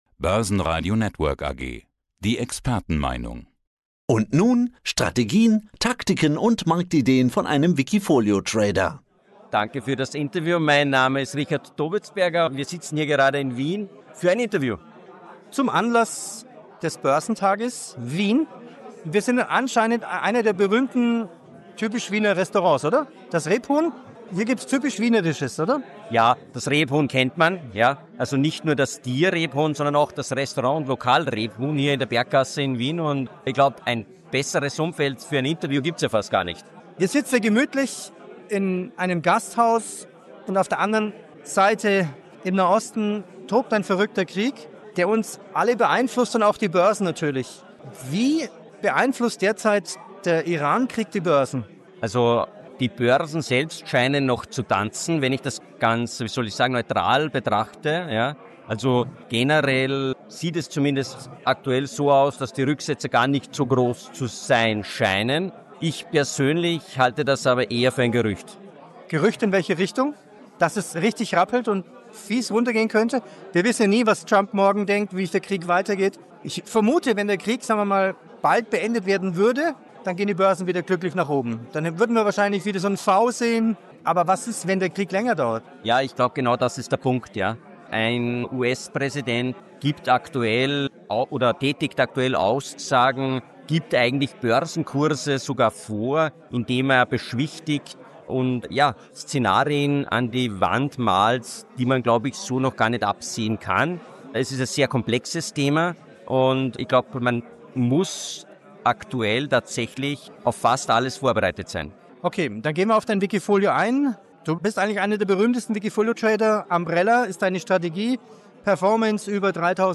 Umbrella-Strategie neu ausgerichtet. Das ganze Interview jetzt auch als Audio im Blog verfügbar.